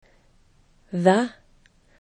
thðə